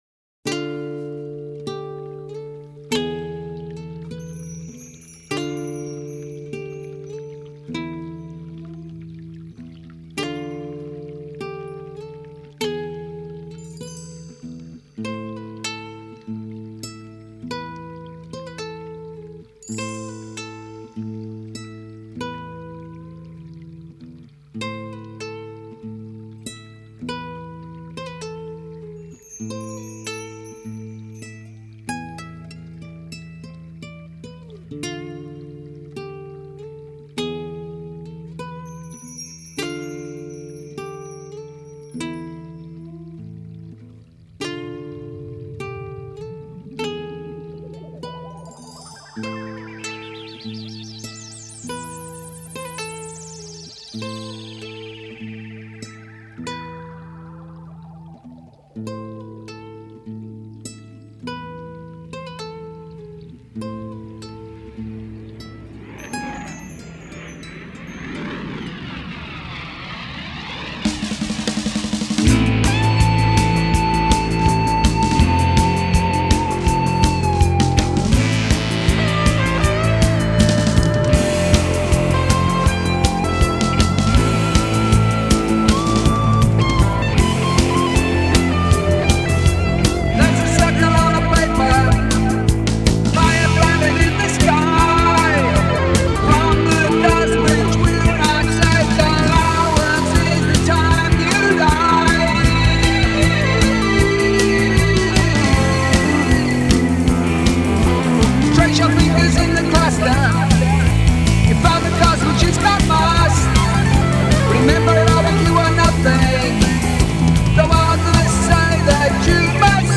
Psychedelic Rock